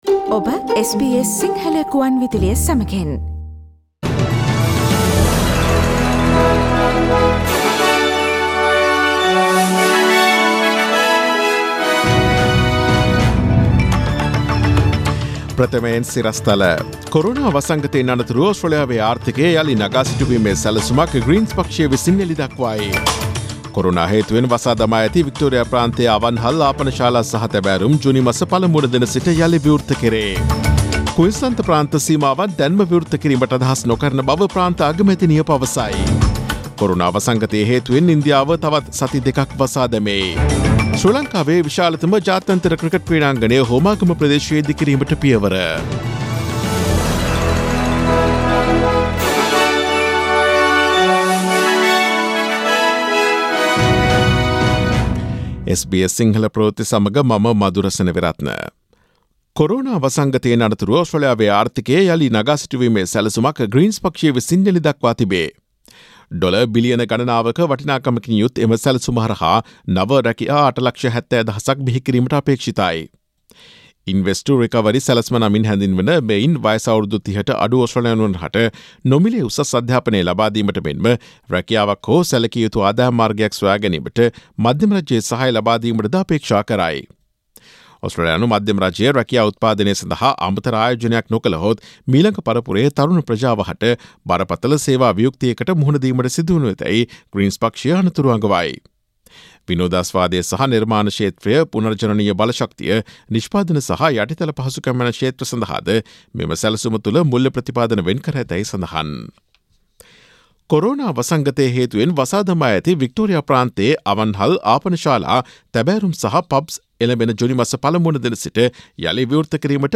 Daily News bulletin of SBS Sinhala Service Source: SBS